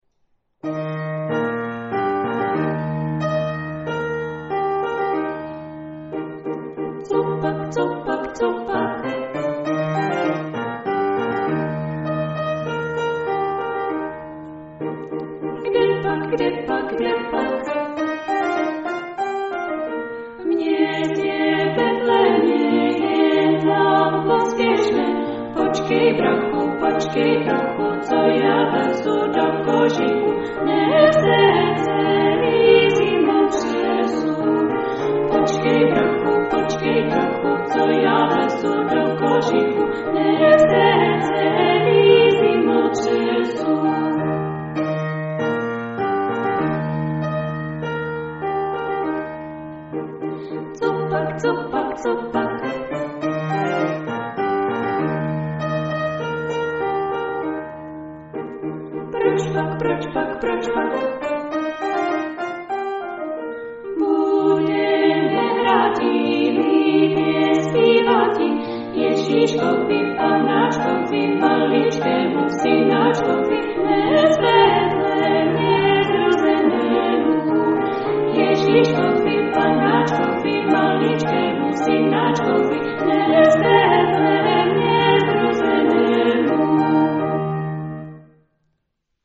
jak jim to pěkně zní dohromady!